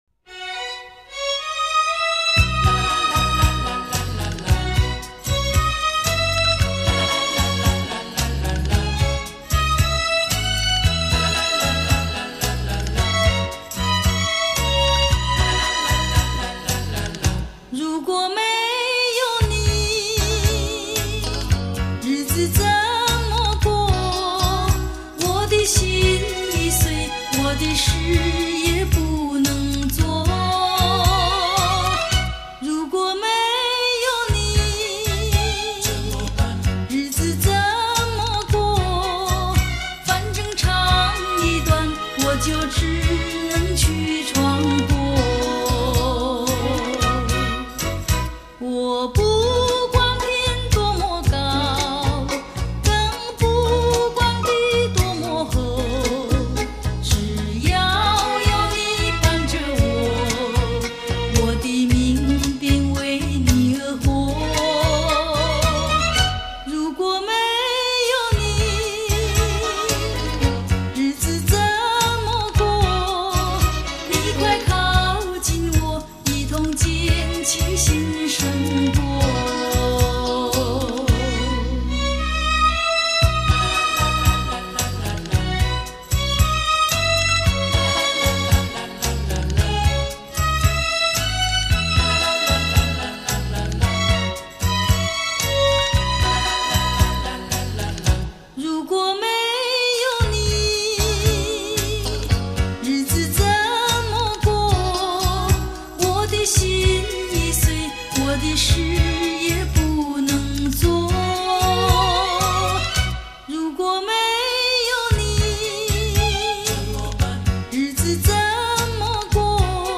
算是比较早的原版带了，
倒是有些流行风格，